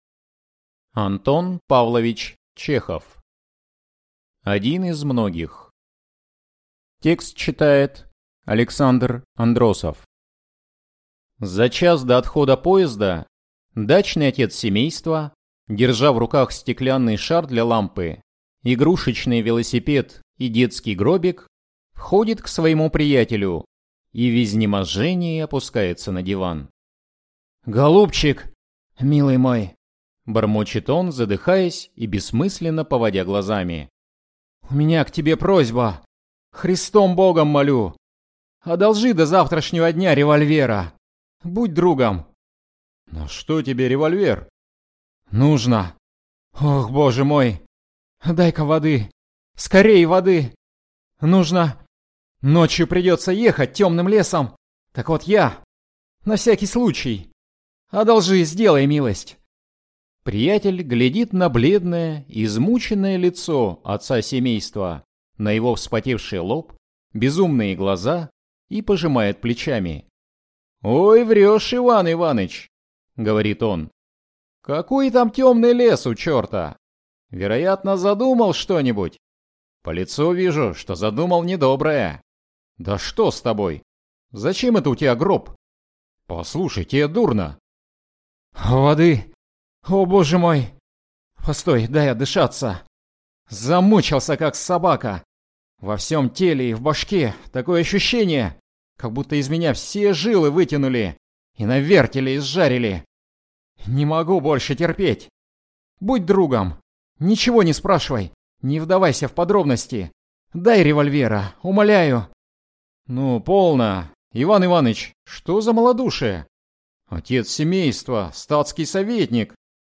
Aудиокнига Один из многих